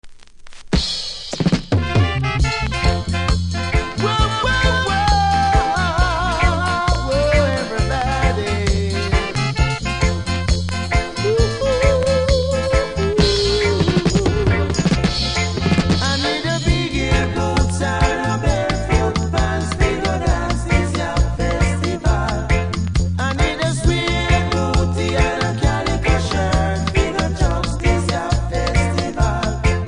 Disco Mix